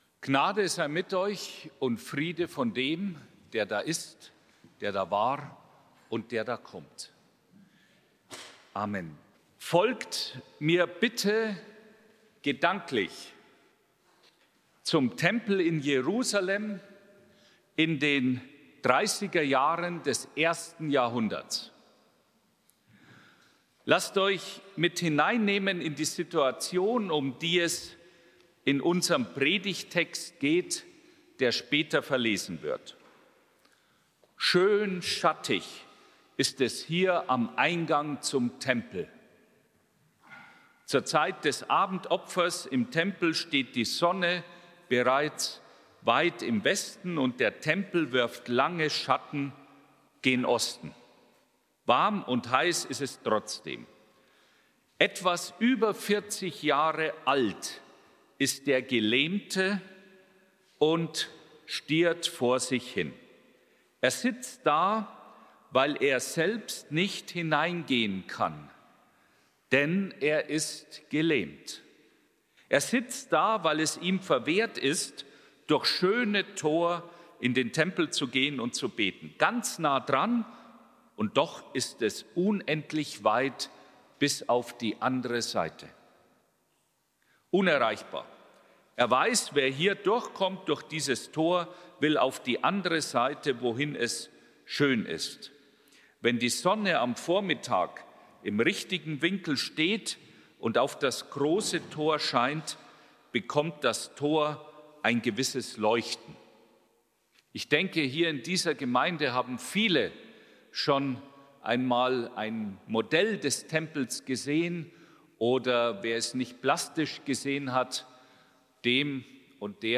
Predigt
Festgottesdienst